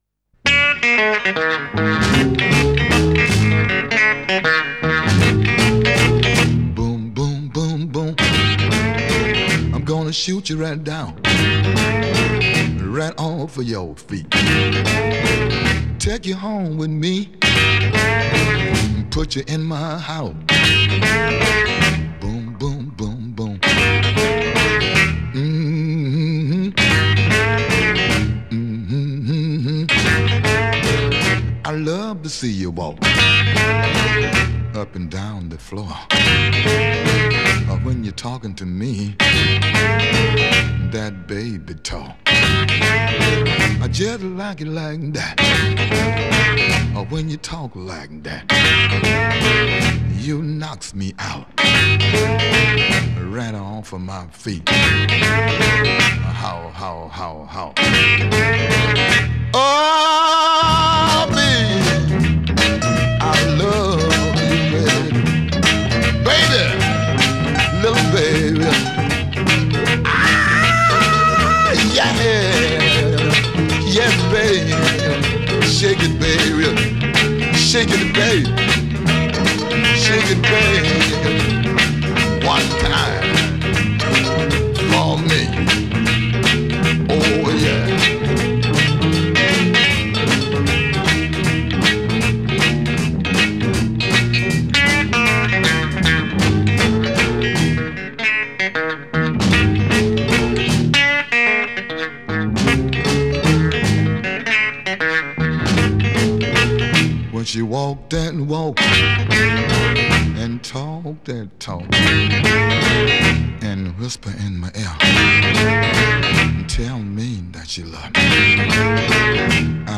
Жанр: Блюз